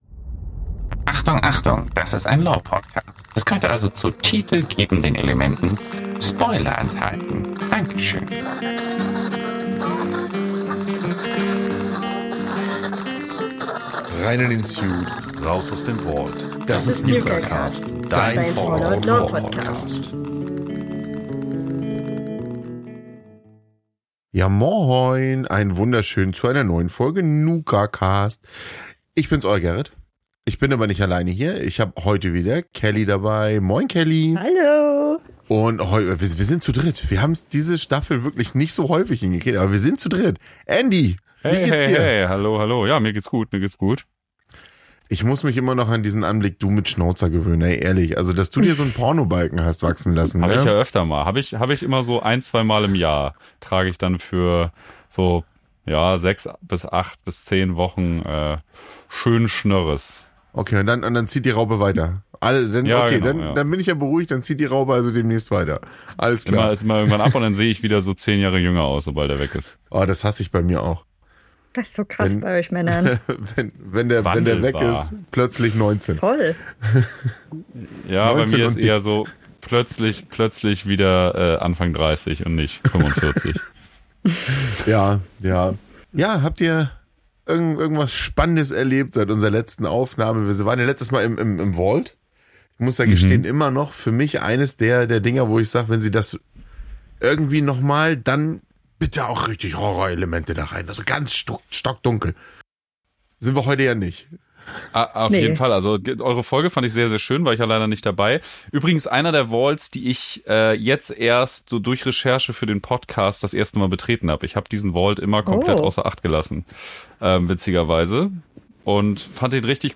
Wir sind wieder zu dritt und klettern heute auf einen der höchsten Türme des Capital Wastelands: Den TENPENNY TOWER! Dieses Feriendomizil für Reiche ist nicht nur das Ziel der Begierde für die örtliche Ghoulpopulation, sondern auch Ziel unserer drei Podcast Hosts!